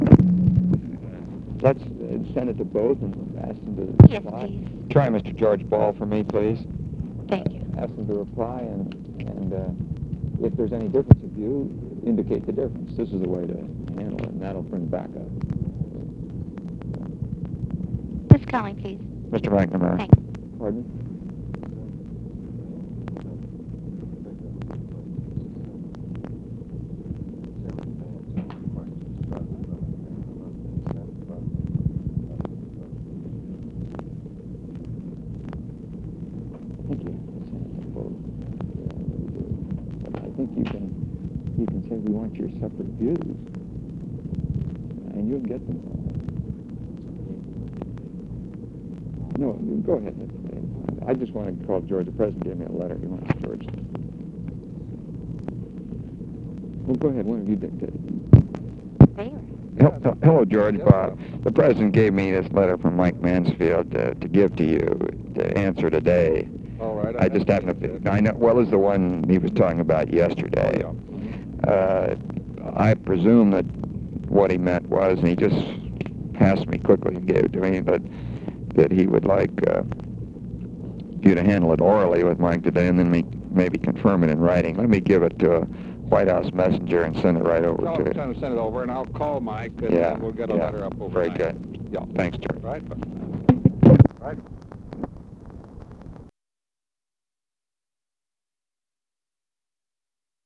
Conversation with GEORGE BALL, OFFICE CONVERSATION and ROBERT MCNAMARA
Secret White House Tapes